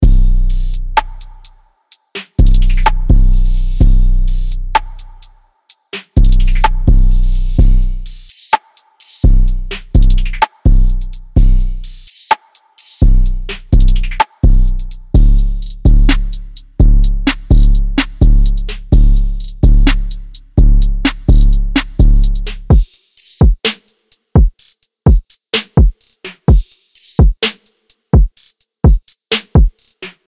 アトランタスタイルの808ベースとトラップビートが弾けるリズムサウンドを解き放つ
・力強いトラップ・ビートとチューニングされた808が、磨き上げられた荒々しさを湛える
プリセットデモ